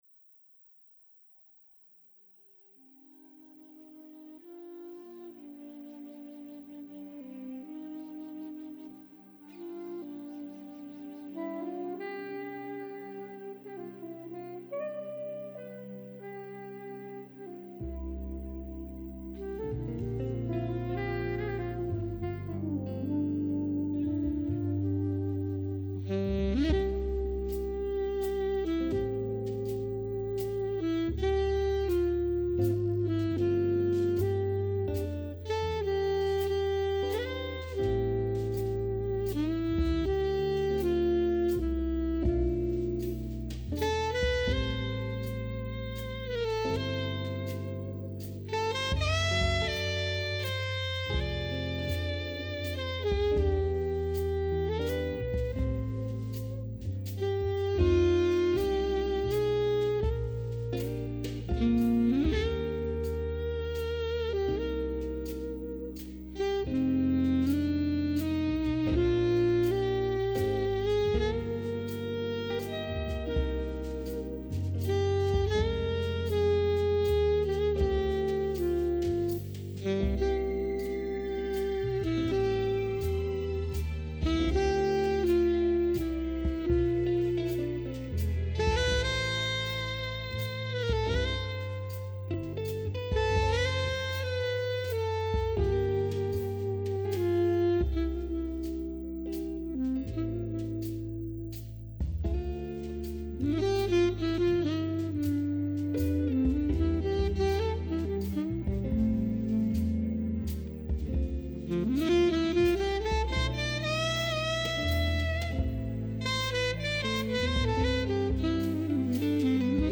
interprétation instrumentale
saxophoniste